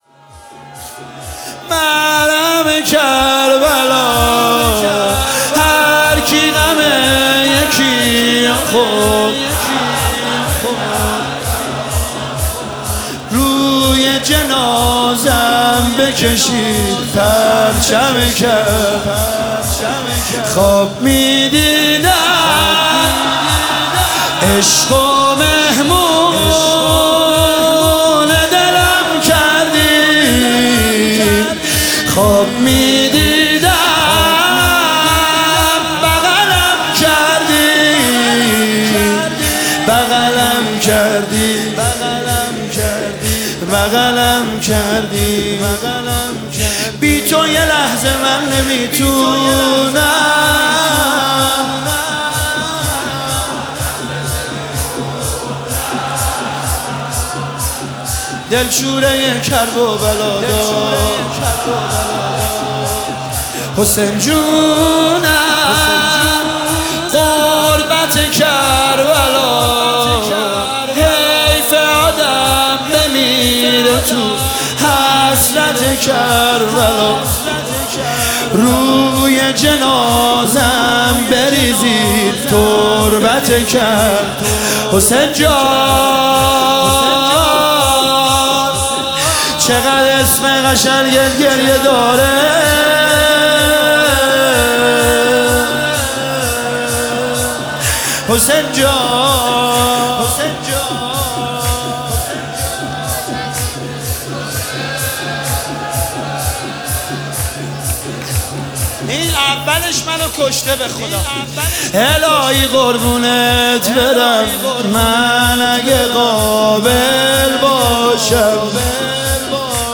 هرکی غم یکی رو خورد من غم کربلا - شور شب دوم فاطمیه 1403